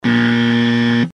Wrong answer